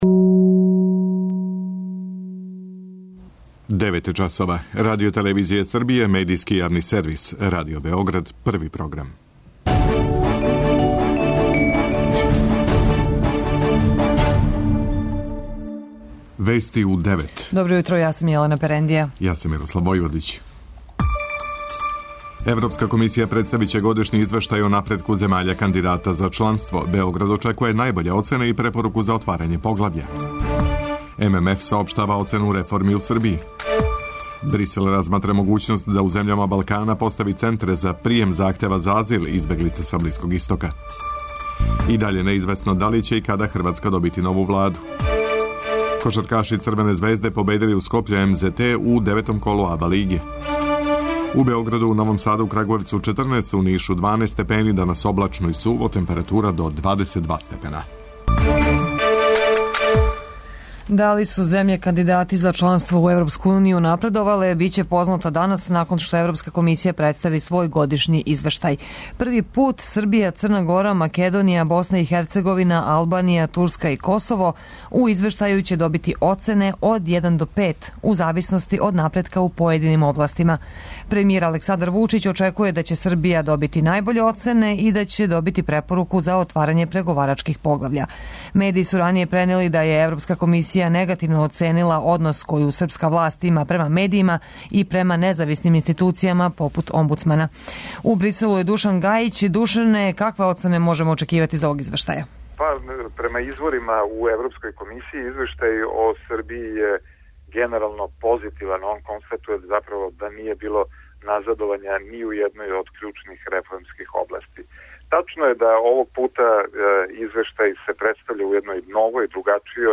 преузми : 9.88 MB Вести у 9 Autor: разни аутори Преглед најважнијиx информација из земље из света.